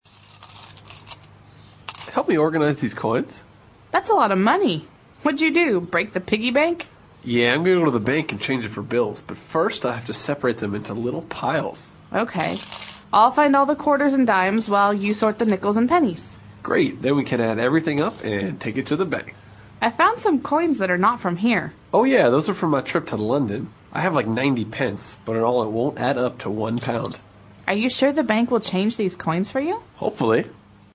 外教讲解纯正地道美语|第340期:Coins and Money 硬币和纸币